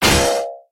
На этой странице собраны разнообразные звуки ударов и урона: резкие атаки, тяжёлые попадания, критические удары.
Комичный звук